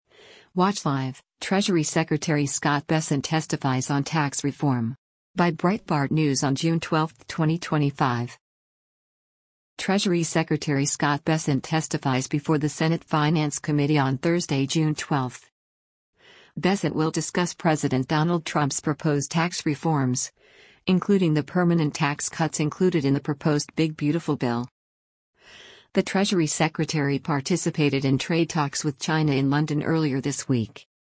Treasury Secretary Scott Bessent testifies before the Senate Finance Committee on Thursday, June 12.